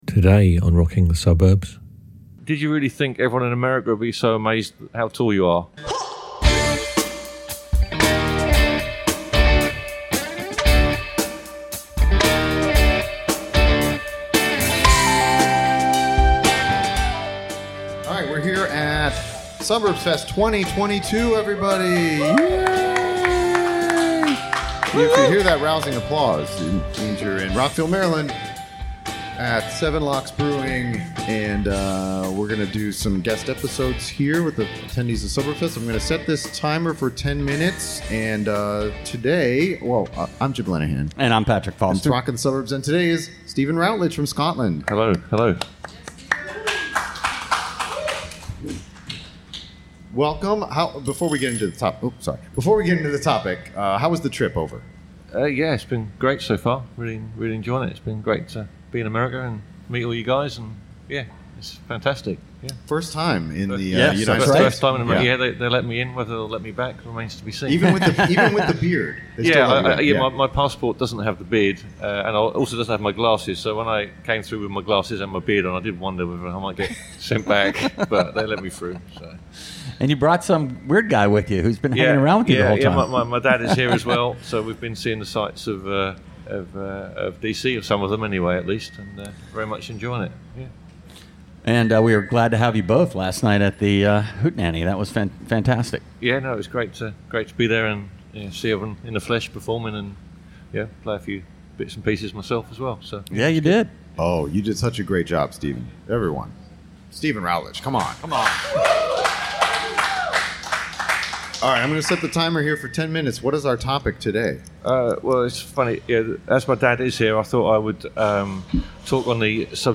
We recorded a bunch of episodes at Suburbs Fest Maryland on September 30.